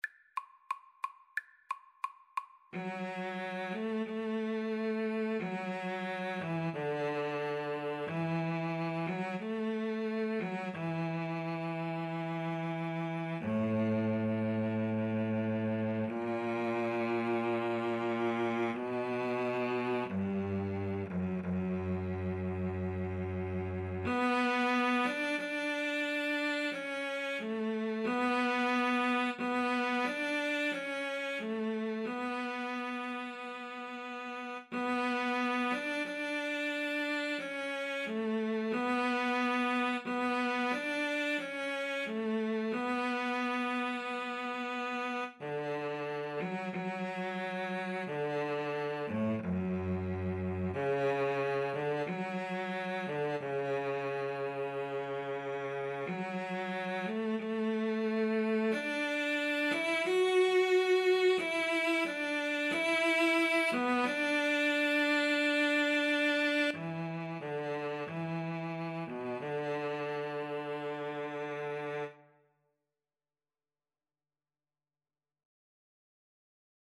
CelloDouble BassDouble Bass (Bass Clef)
=180 Largo
Classical (View more Classical Cello-Bass Duet Music)